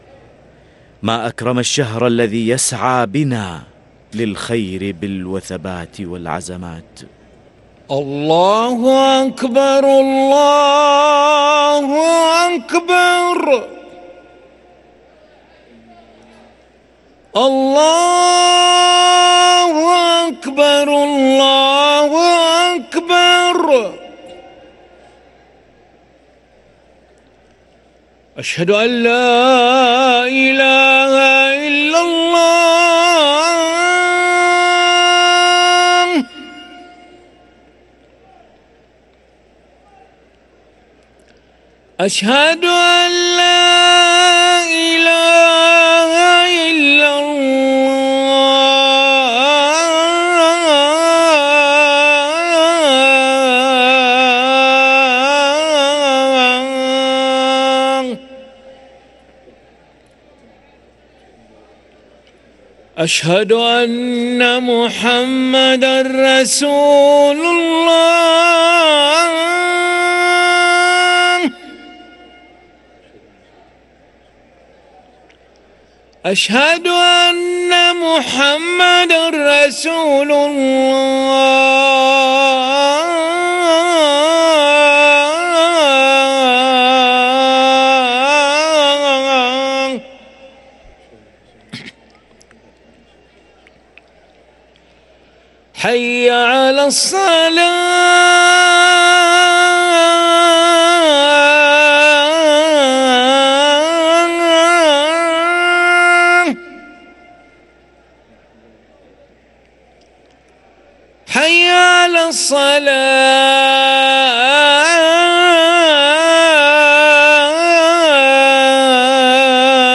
أذان العشاء للمؤذن علي ملا الخميس 15 رمضان 1444هـ > ١٤٤٤ 🕋 > ركن الأذان 🕋 > المزيد - تلاوات الحرمين